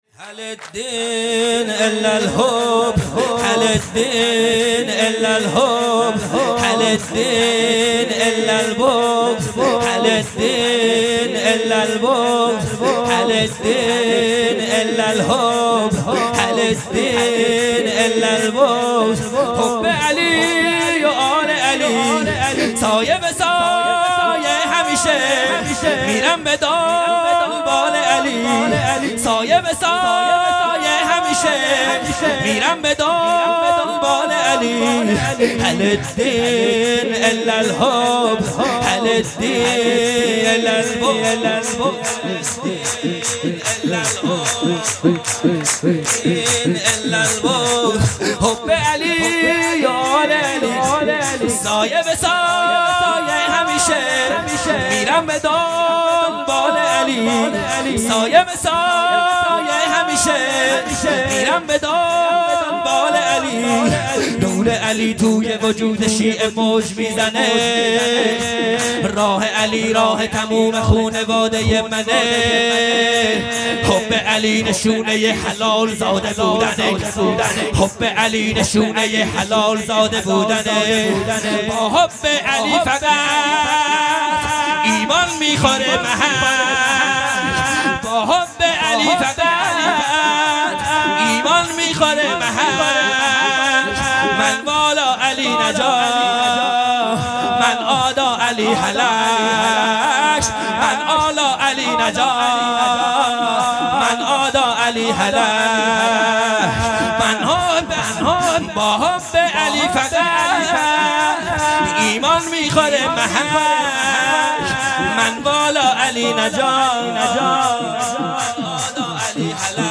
خیمه گاه - هیئت بچه های فاطمه (س) - سرود | هل الدّین الا الحب | 21 بهمن 1400
جلسۀ هفتگی